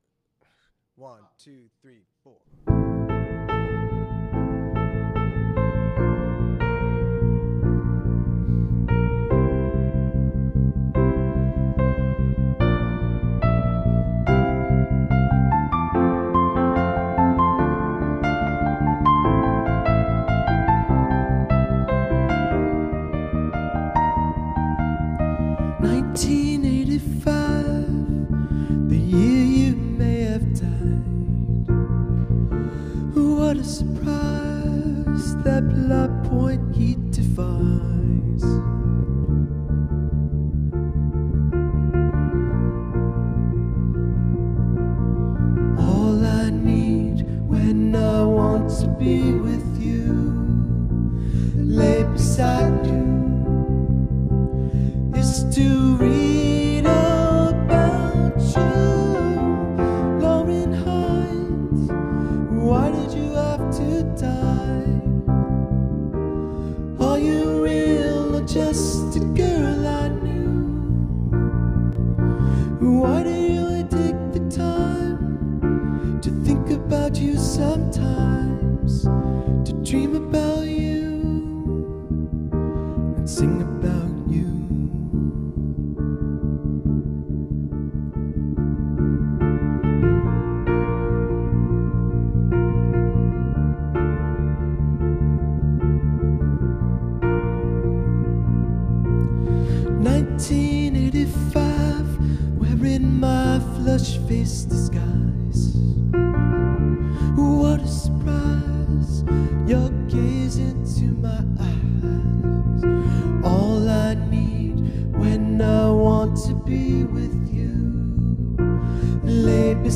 Bass & Backing Vocals